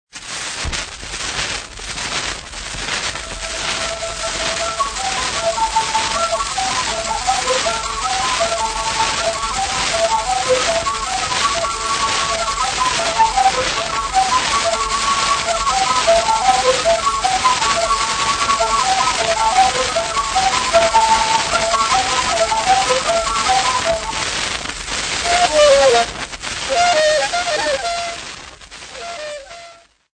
Folk Music
Field recordings
Africa Zimbabwe city not specified f-rh
sound recording-musical
Indigenous music